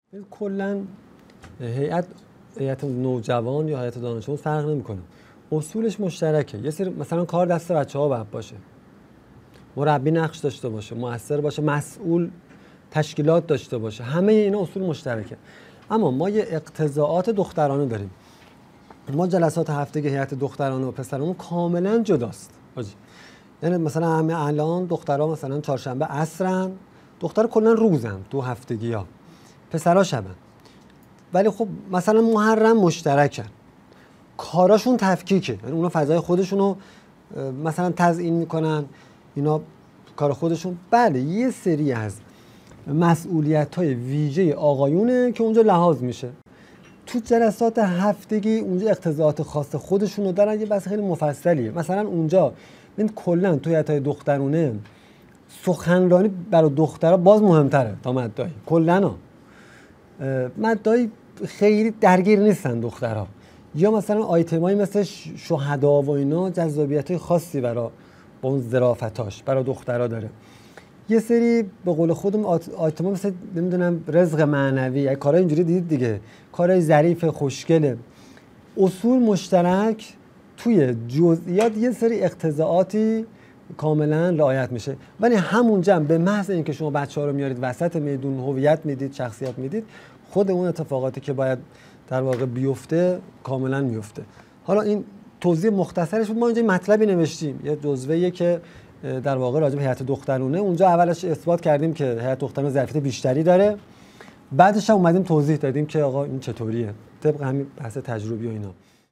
مدرسه عالی هیأت | گزیده دوازدهم از سومین سلسله نشست‌ های هیأت و نوجوانان